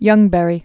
(yŭngbĕrē)